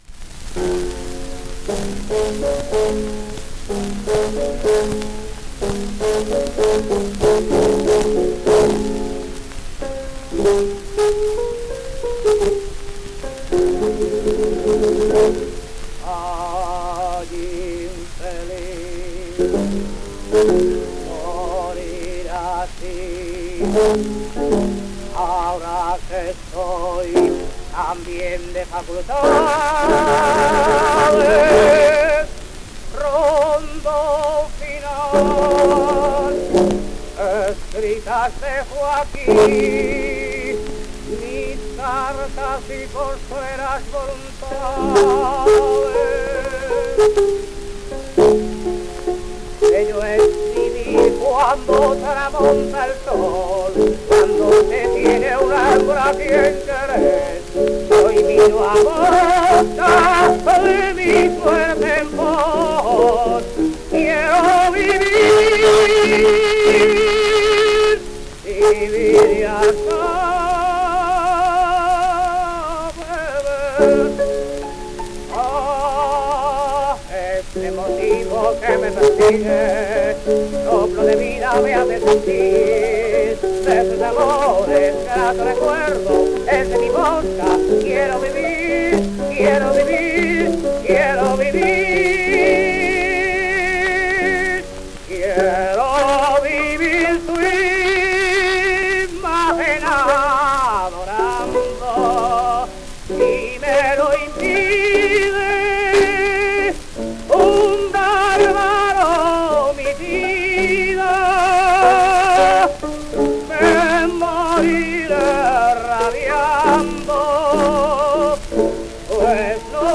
zarzuela tenor